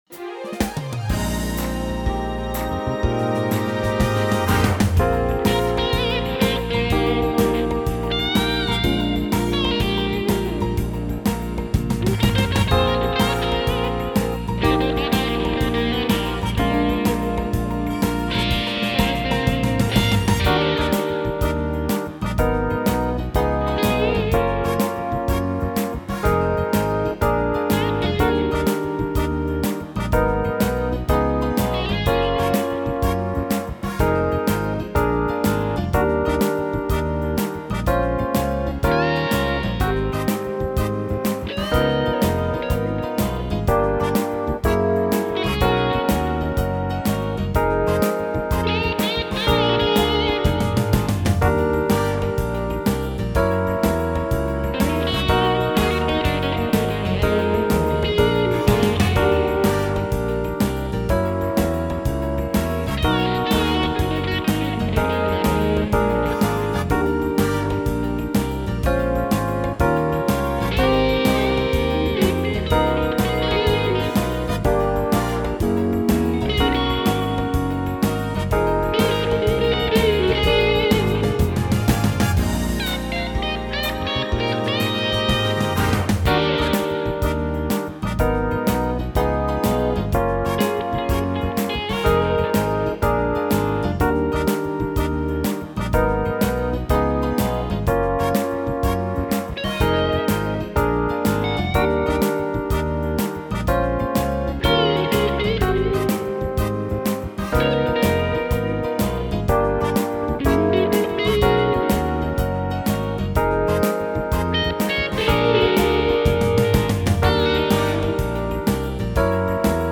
Song style: soul / swing